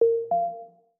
Notification Smooth 1.wav